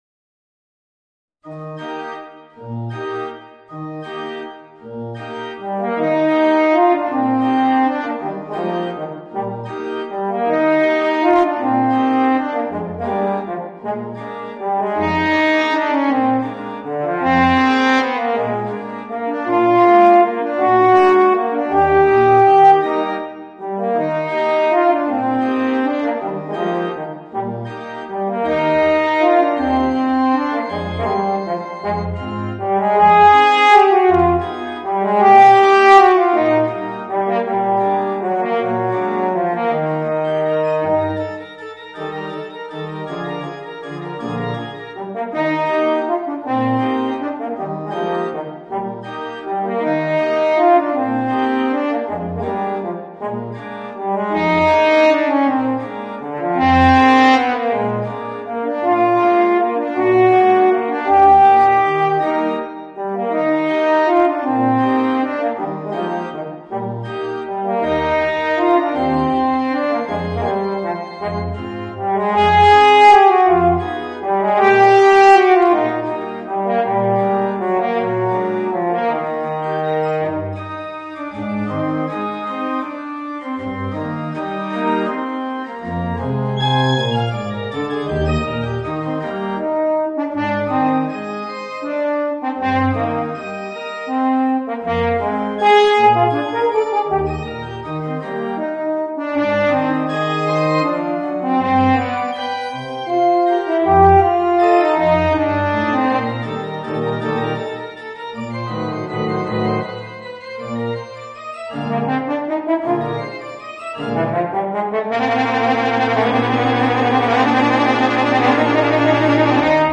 Voicing: Eb Horn and Organ